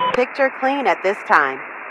Radio-awacsPictureClear4.ogg